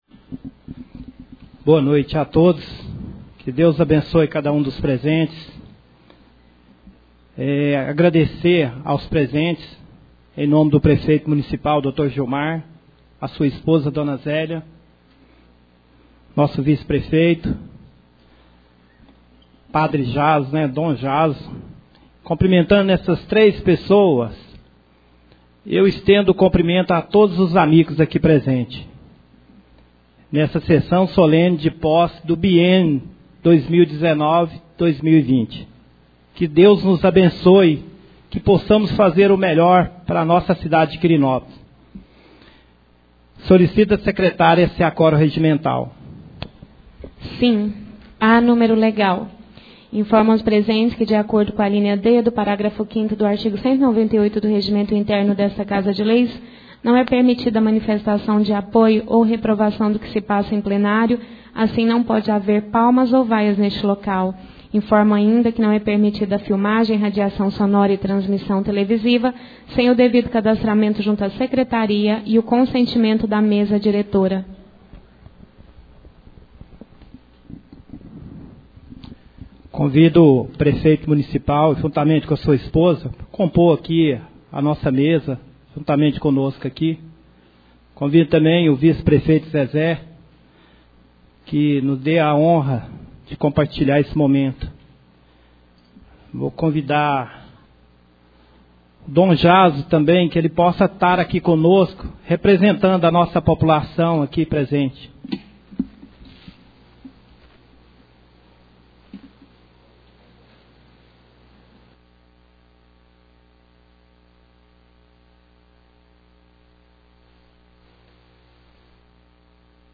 Sessão solene de posse da mesa diretora 2019/2020